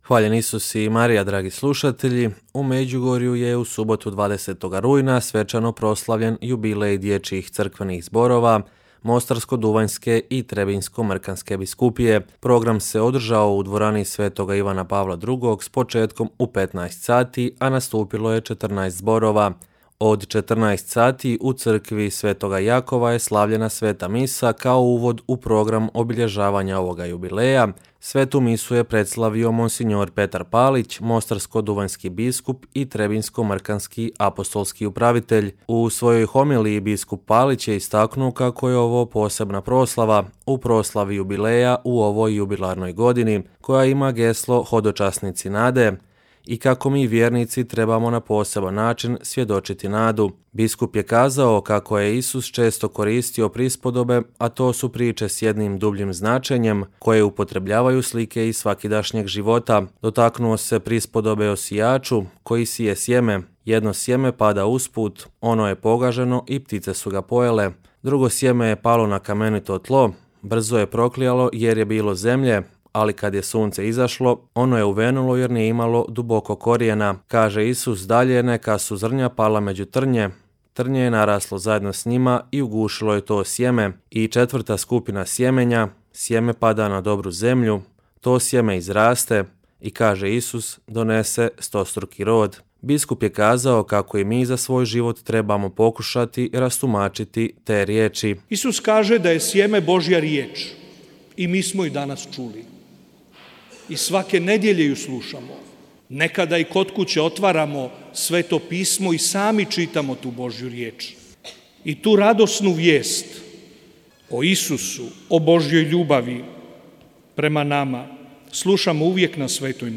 U Međugorju je u subotu 20. rujna svečano proslavljen Jubilej dječjih crkvenih zborova Mostarsko-duvanjske i Trebinjsko-mrkanske biskupije.
Program se održao u dvorani svetoga Ivana Pavla II., a nastupilo je 14 zborova.